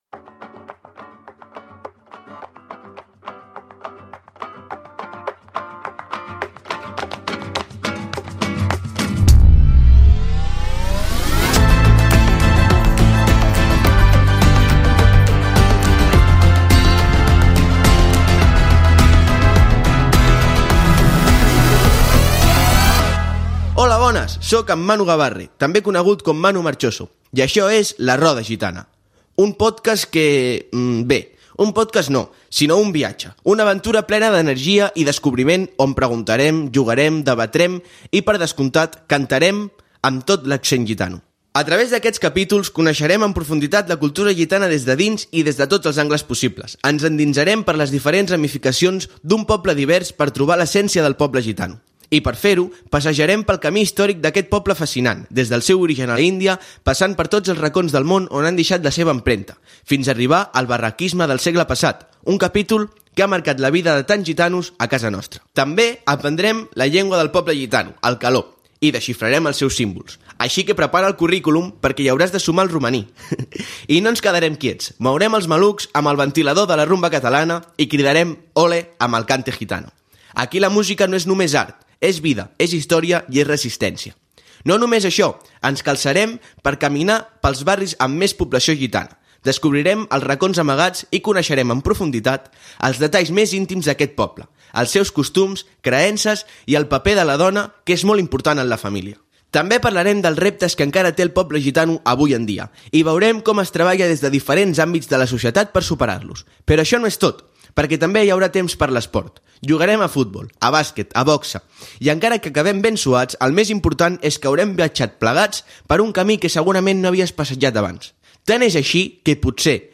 Presentació del primer episodi esmentant els temes que s'hi tractaran. Què en sap la població de Barcelona dels gitanos. Entrevista